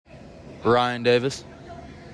Pronunciation:  RYE in DAY vis